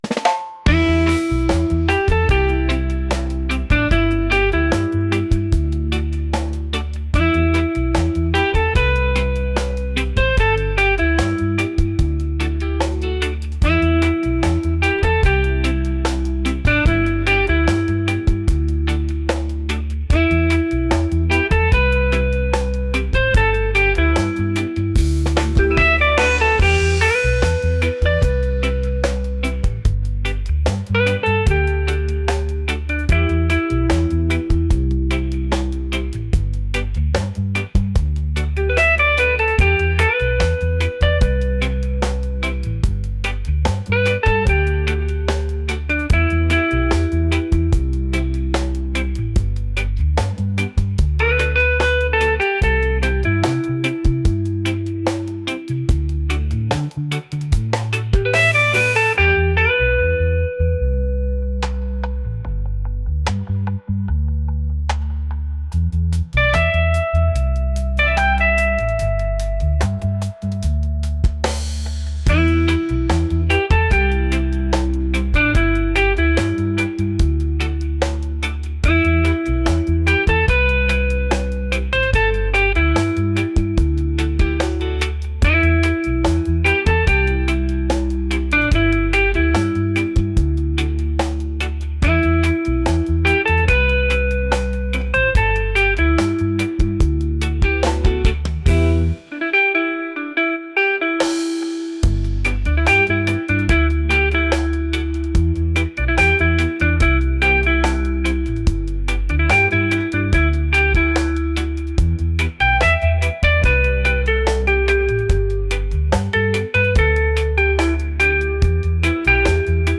reggae | soul | groovy